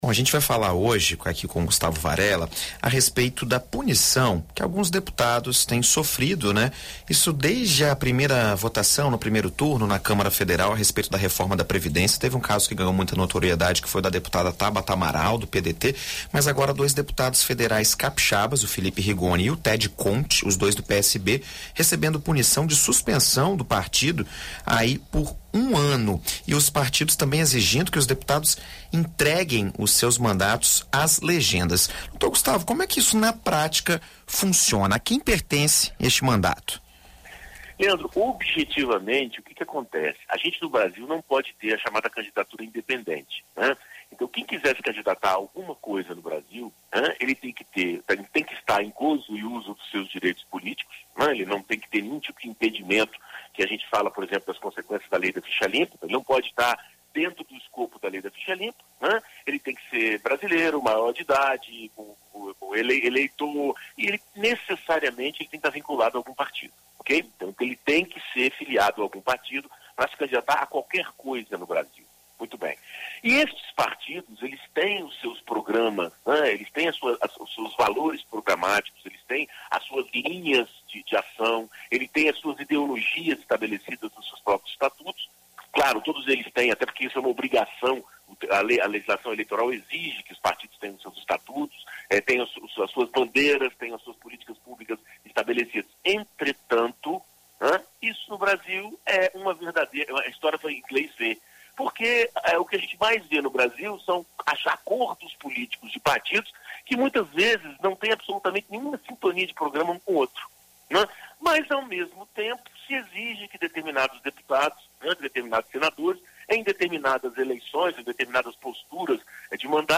Na coluna Direito Para Todos desta segunda-feira (2), na BandNews FM Espírito Santo